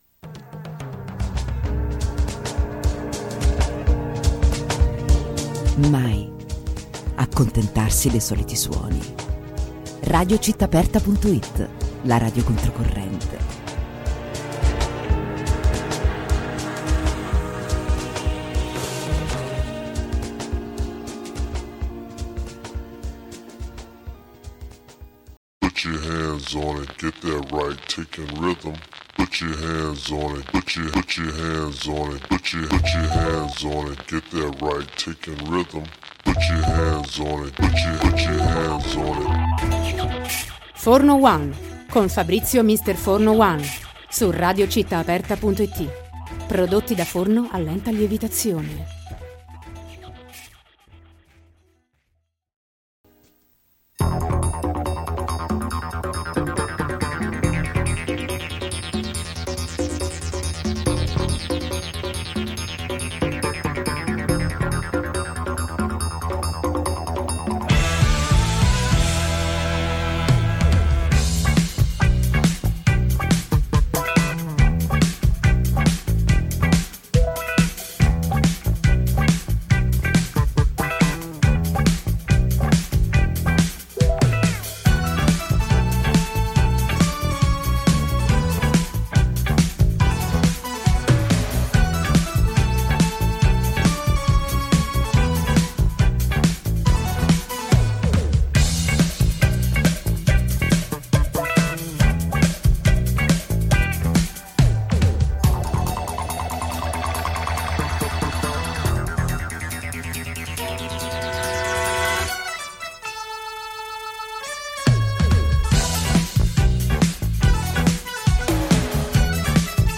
Intervista al Mago del Gelato